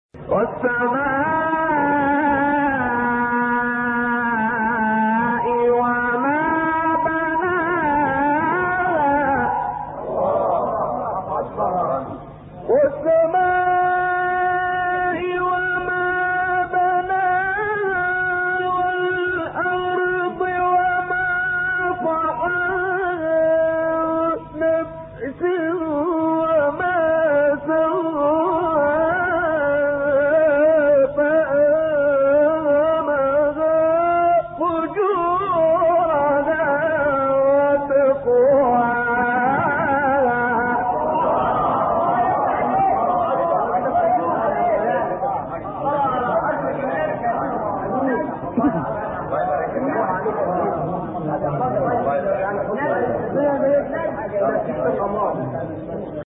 گروه شبکه اجتماعی: مقاطعی صوتی از تلاوت قاریان برجسته مصری را می‌شنوید.
مقطعی از شعبان عبدالعزیز صیاد/ سوره شمس در مقام نهاوند